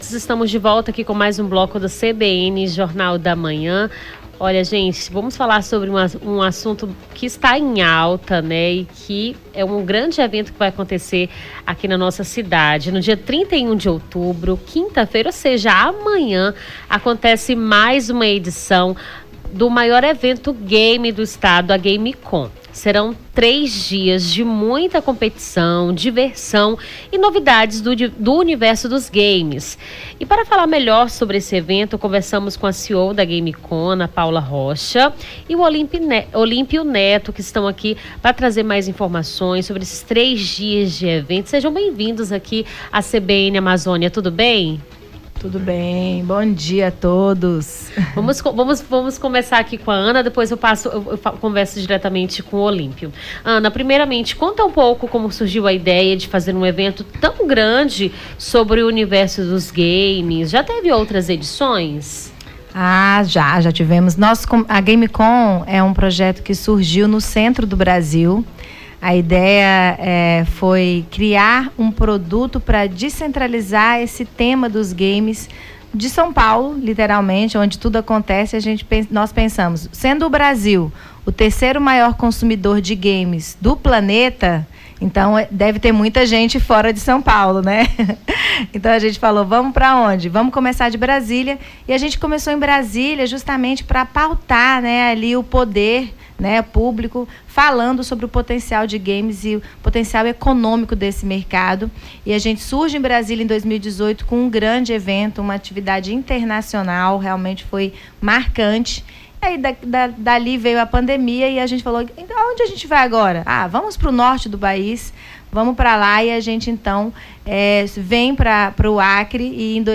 Nome do Artista - CENSURA- ENTREVISTA GAMECON (30-10-24).mp3